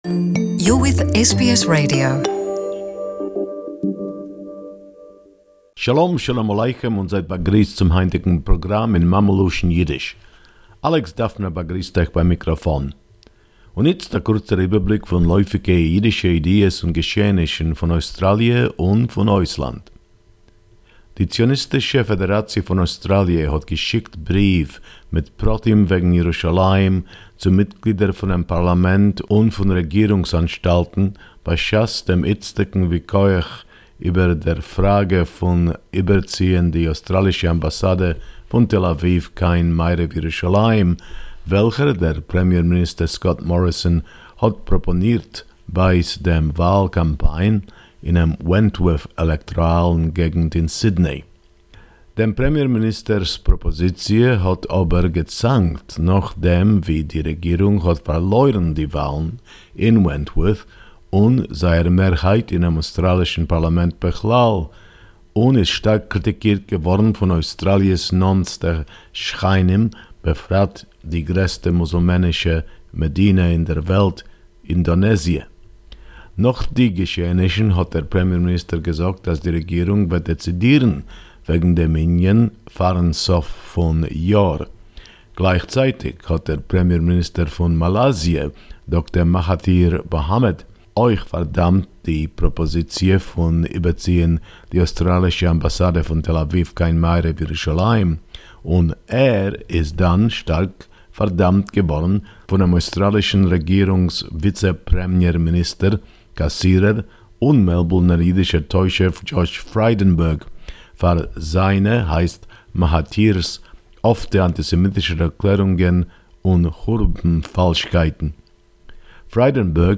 Yiddish report